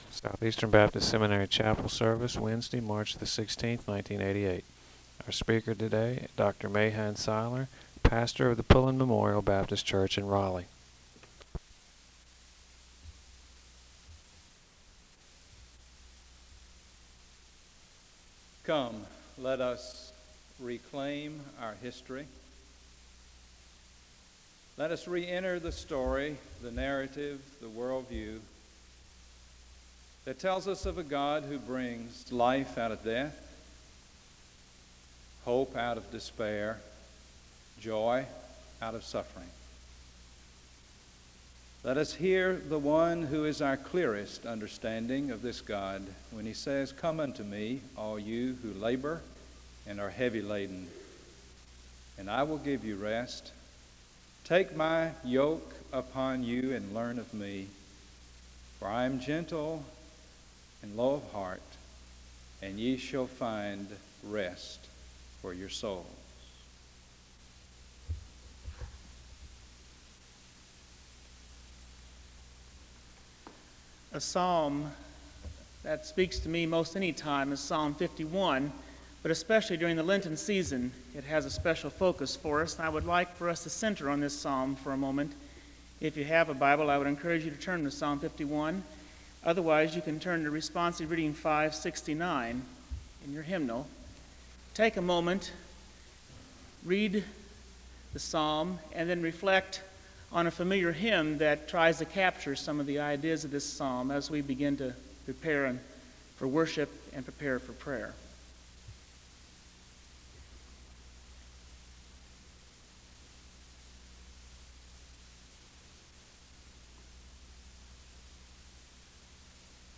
The service begins with a call to worship and a hymn based on Psalm 51 (0:00-4:08). There is a moment of prayer (4:09-7:14). There is a song of worship (7:15-8:37). Prayer concerns are shared with the congregation and there is a moment of prayer (8:38-11:20).
Location Wake Forest (N.C.)